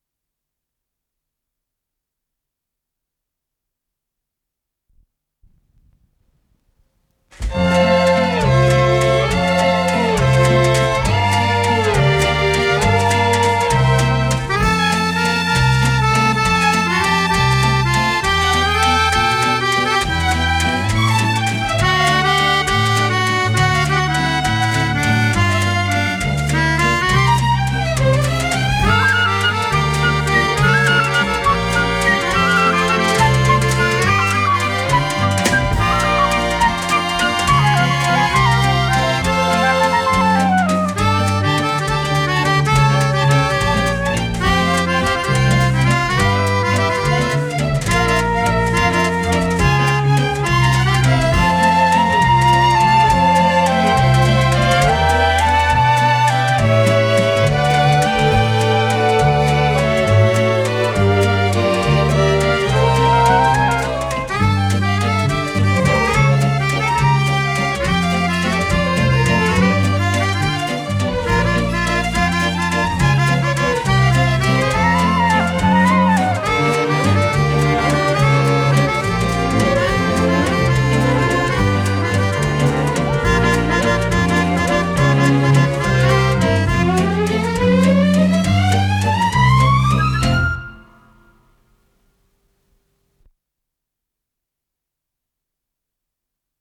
ПодзаголовокЗаставка, фа мажор
ВариантДубль моно